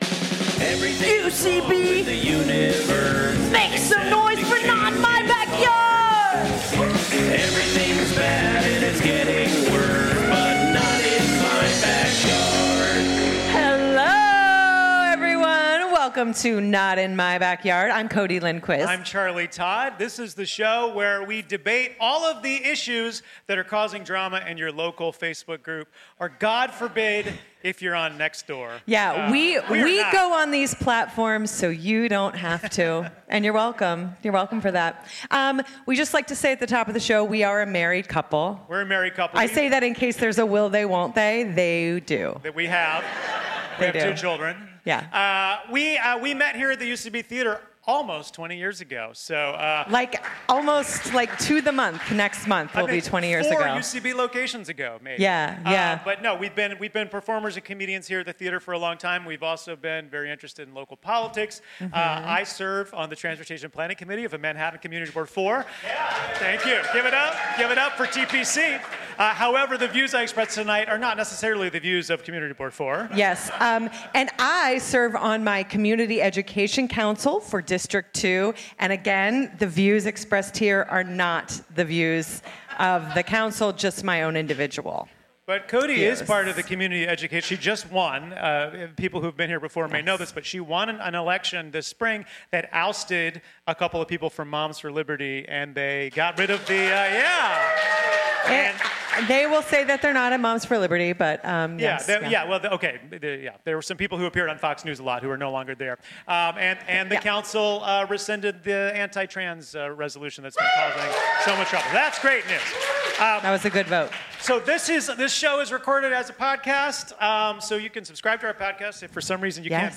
Live form the UCB Theatre NY, we discuss mayoral endorsements, NYPD parking habits, rats in strollers, and public urination.